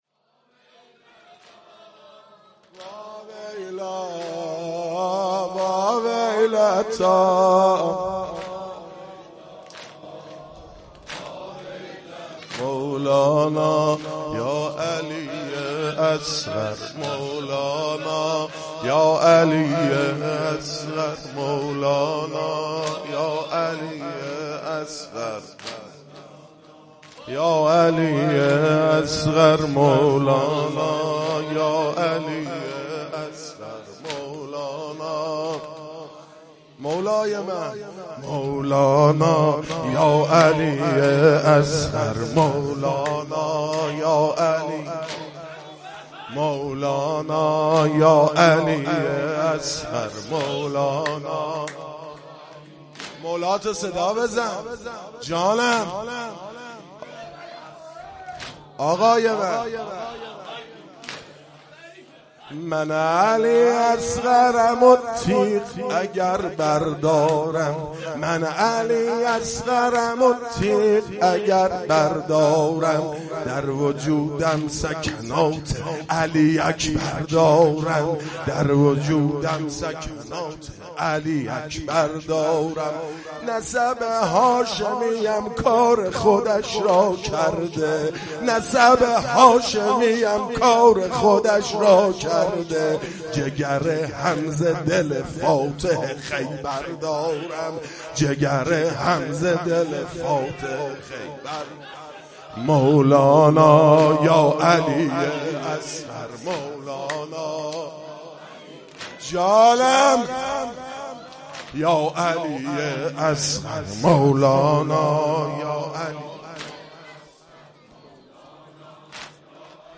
مداحی شب هفتم محرم 98 ( واحد )